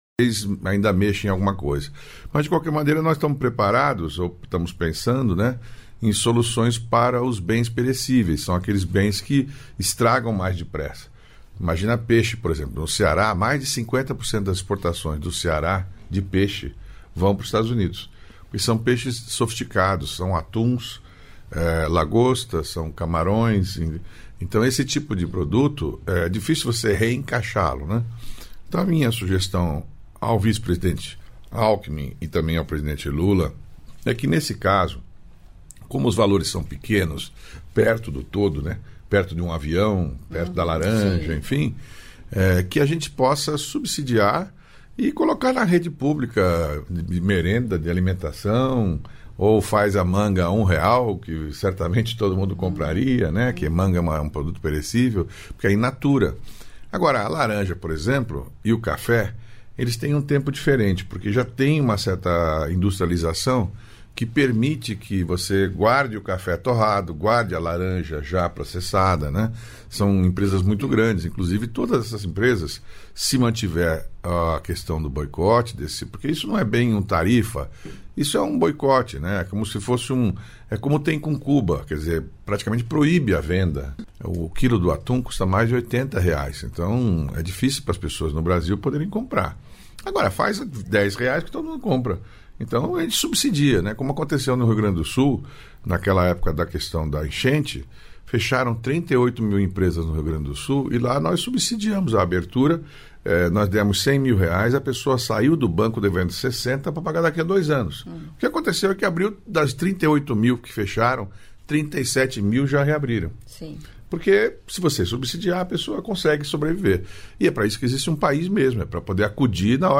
Trecho da participação do ministro do Empreendedorismo, da Microempresa e da Empresa de Pequeno Porte, Márcio França, no programa "Bom Dia, Ministro" desta quarta-feira (30), nos estúdios da EBC em Brasília (DF).